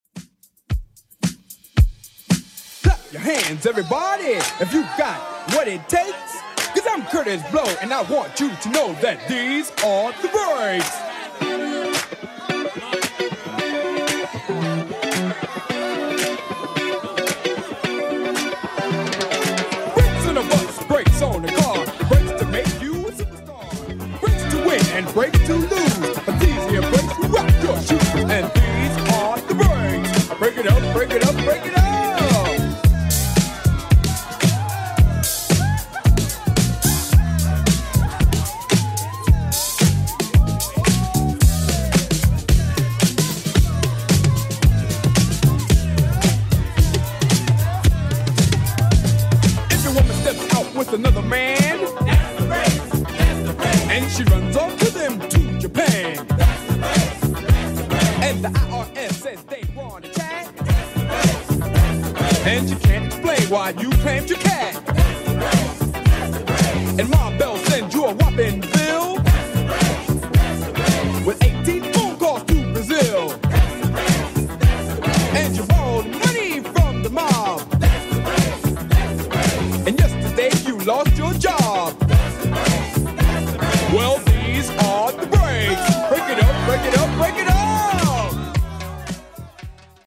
BPM: 112 Time